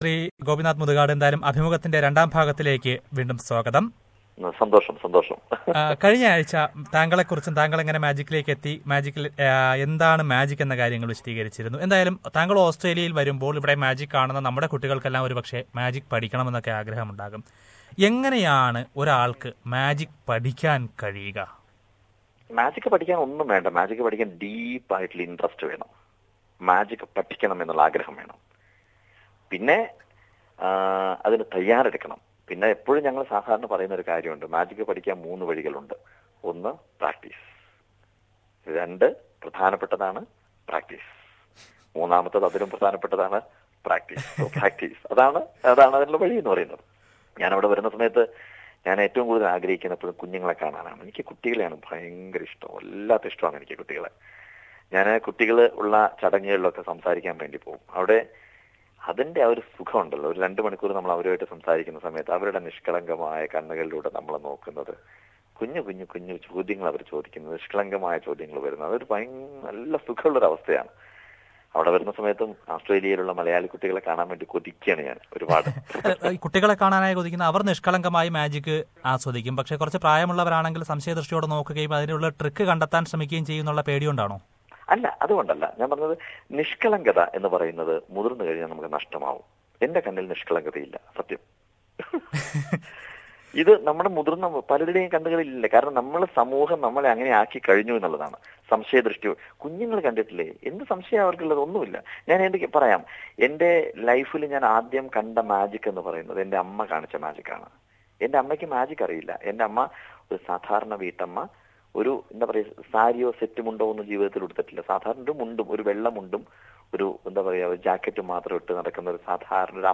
Famous illusionist Gopinath Muthukad has talked to SBS Malayalam Radio last week about his entry to magic as a profeesion and the secret behind magic. In the second part of the interview, he talks about how to learn magic and how magic is being misused by some people for personal gain.